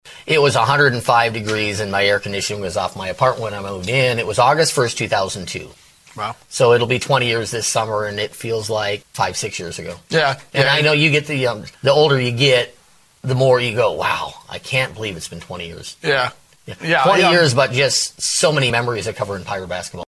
Pirates Digital Media provided the audio for the interview